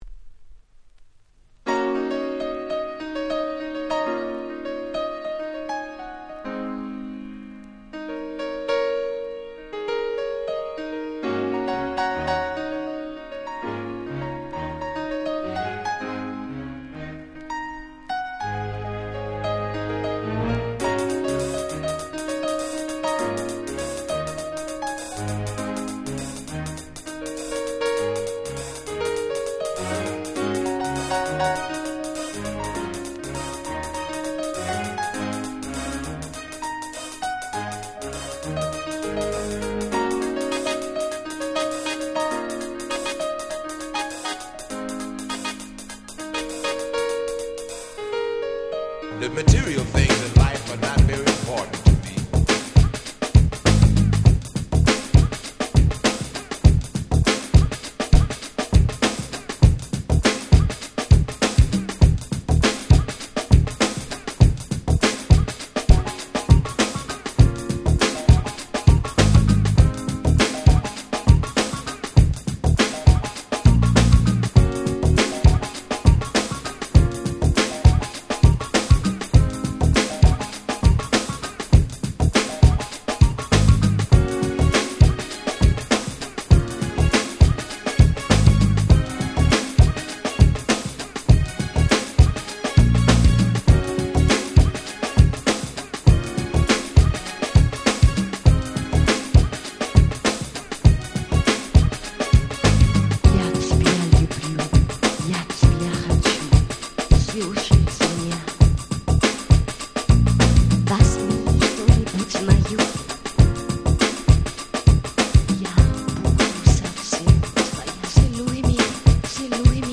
90年という年代柄、内容は流行りのGround Beatが多め。
グラウンドビート グランド Grand グラビ